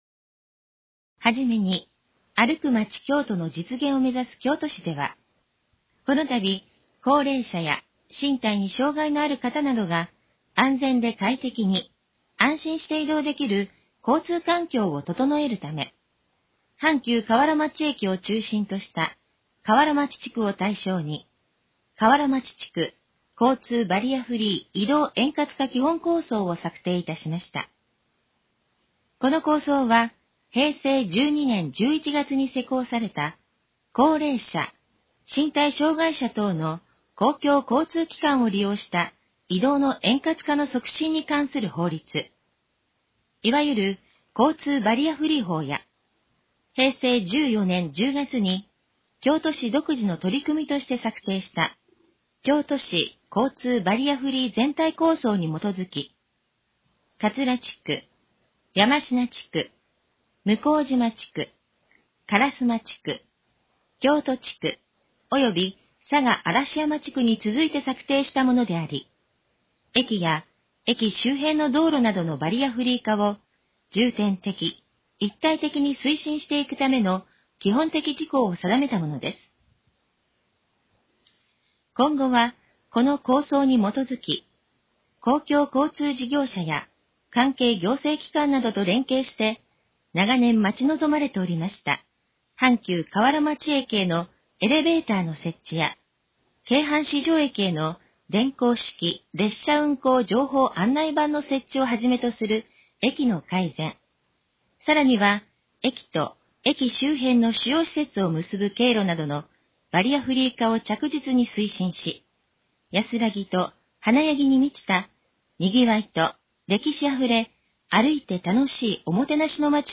このページの要約を音声で読み上げます。
ナレーション再生 約282KB